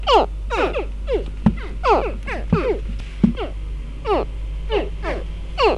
Les cris d’éclosion des petits crocodiles encore dans l’œuf.
Ces sons, audibles à travers la coquille et à l’extérieur du nid, remplissent une double fonction.